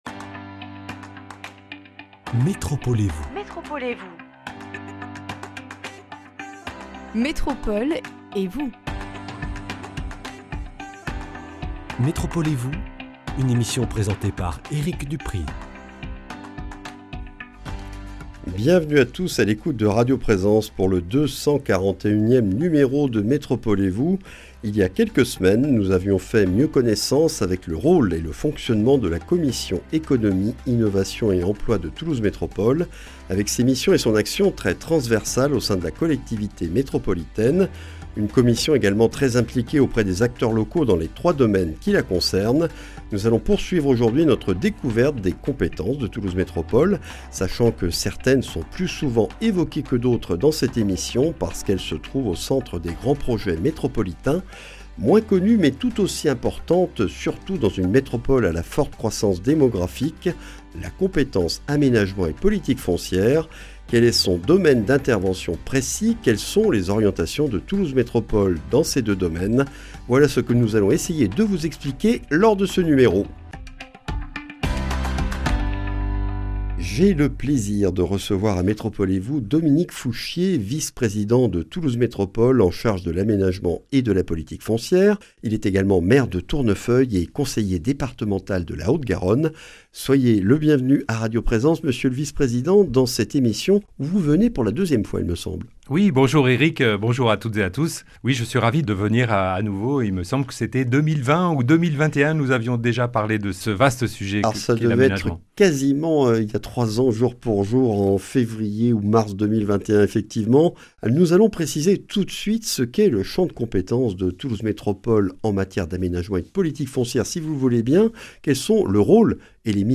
Quelles contraintes la collectivité métropolitaine doit-elle prendre en compte pour poursuivre son essor économique et accueillir de nouveaux habitants tout en préservant l’environnement et la qualité de vie sur son territoire ? Des réponses avec Dominique Fouchier, maire de Tournefeuille, conseiller départemental de la Haute-Garonne, et vice-président de Toulouse Métropole chargé de l’Aménagement et de la Politique foncière.